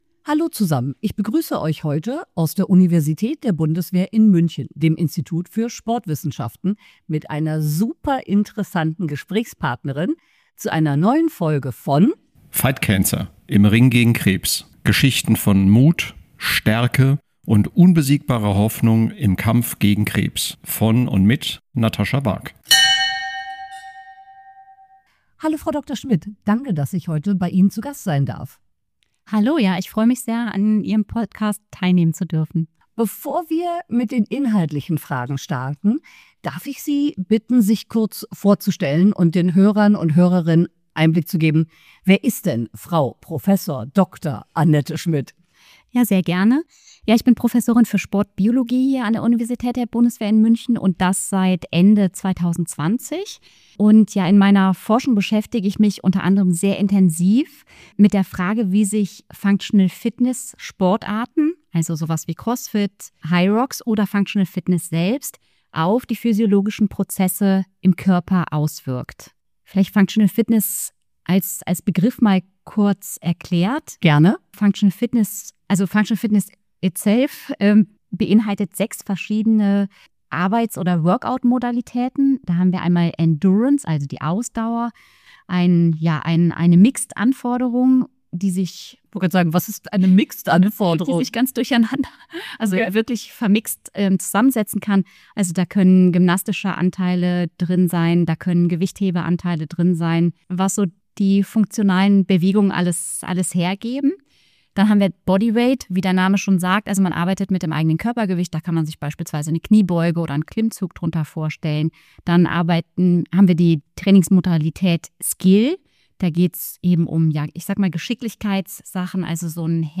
Im Interview haben wir kurz natürliche Killerzellen angesprochen und ich habe versprochen, hier in den Shownotes Informationen dazu zu geben.